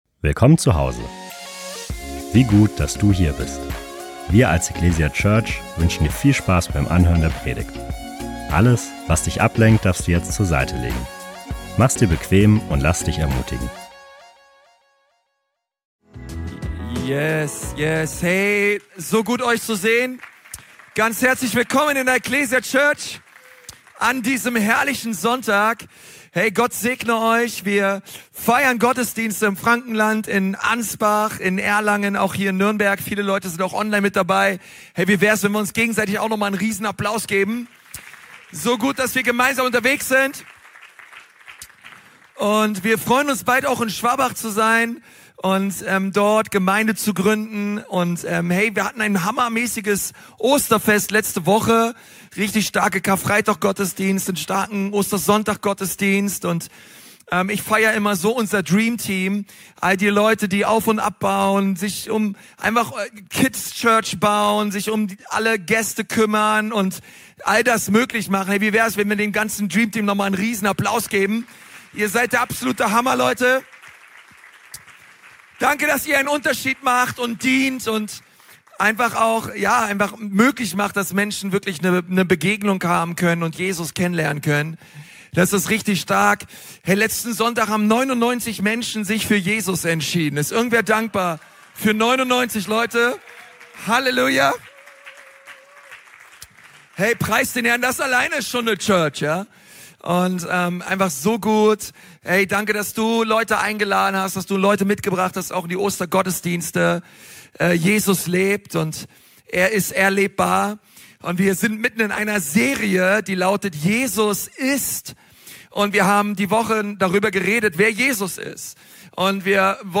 Die Bibelstellen zur Predigt und eine Möglichkeit aktiv mitzuschreiben, findest du in der digitalen Predigtmitschrift.